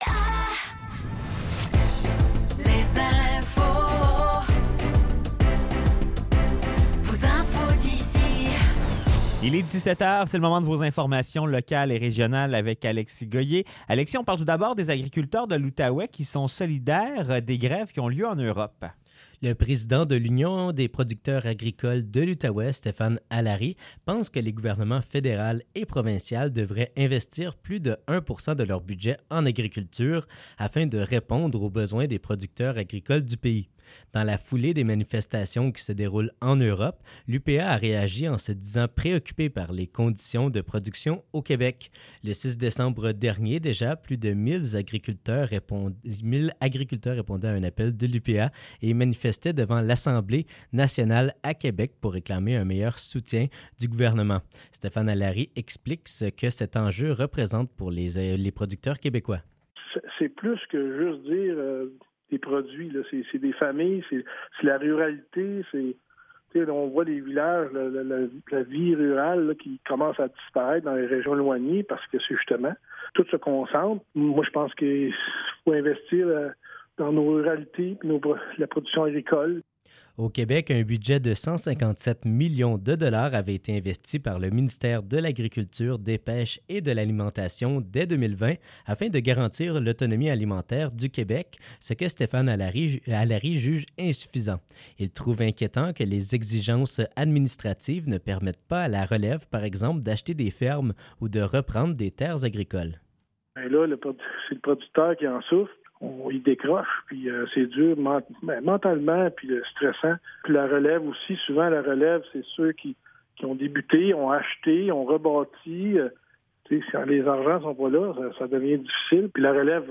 Nouvelles locales - 14 février 2024 - 17 h